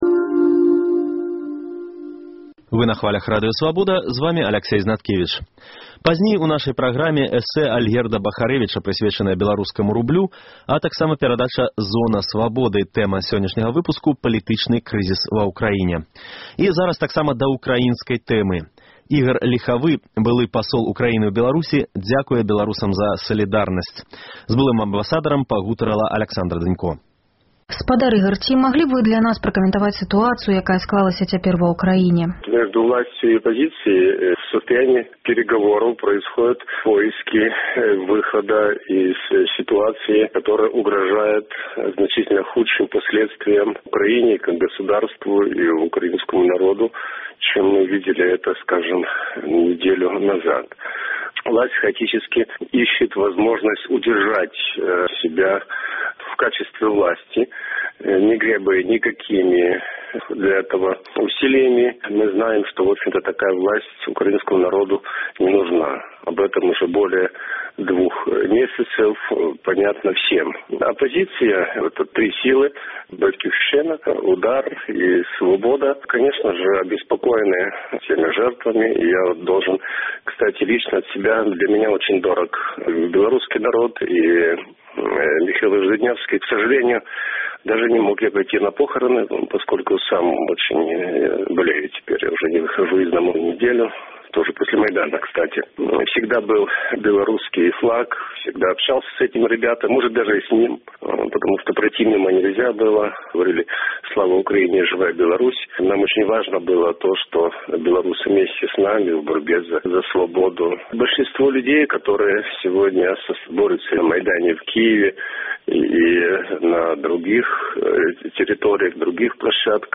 Інтэрвію з Ігарам Ліхавым, былым амбасадарам Украіны ў Беларусі